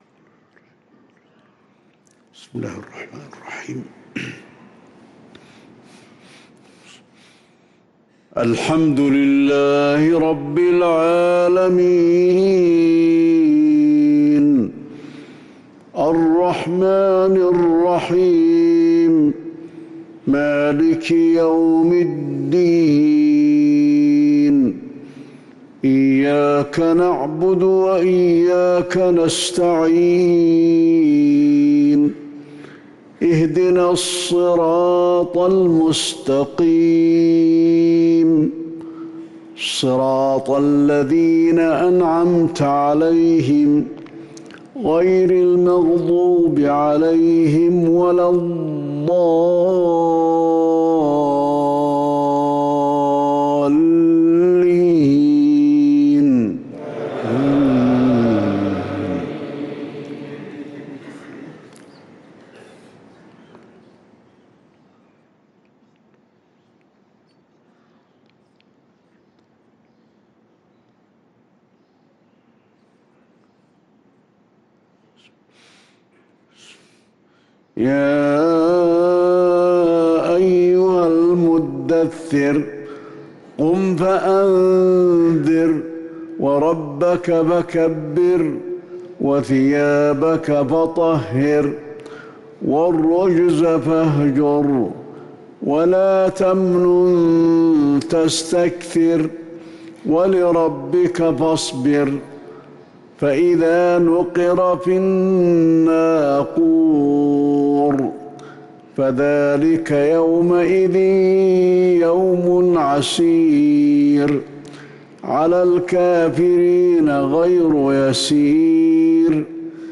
صلاة الفجر للقارئ علي الحذيفي 25 ربيع الآخر 1445 هـ
تِلَاوَات الْحَرَمَيْن .